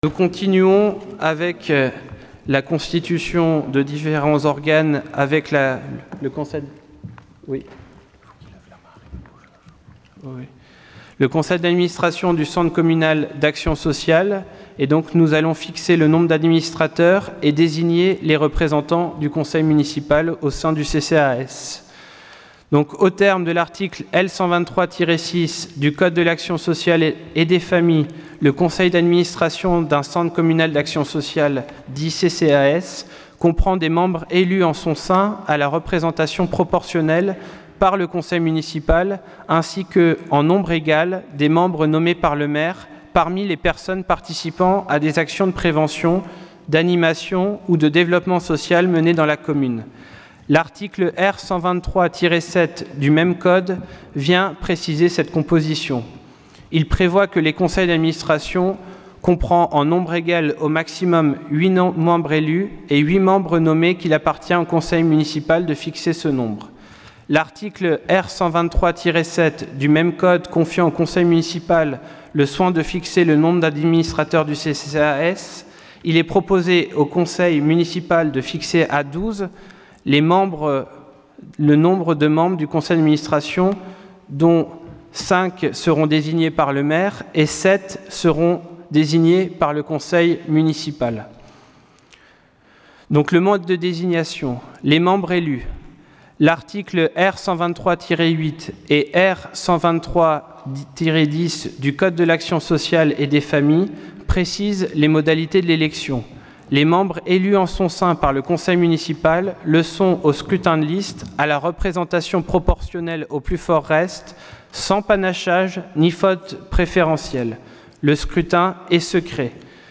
Point 6 : Constitution de la commission d’appel d’offres Conseil Municipal du 04 juillet 2020